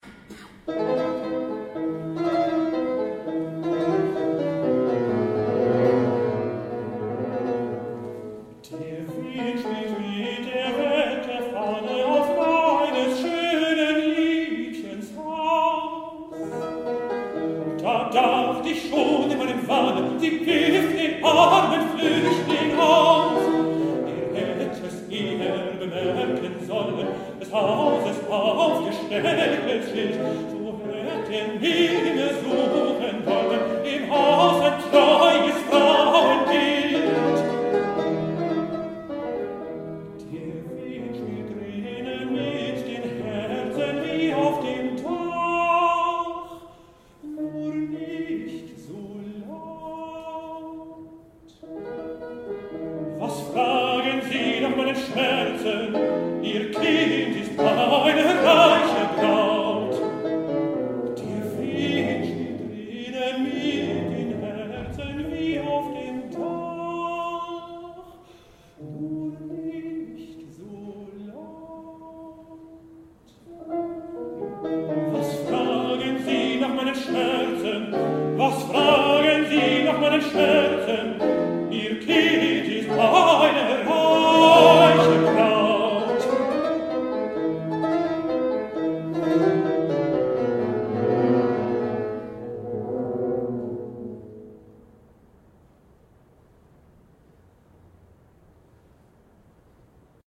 Tenor
Hammerflügel
Livemitschnitt eines Konzertes vom 05.11.2012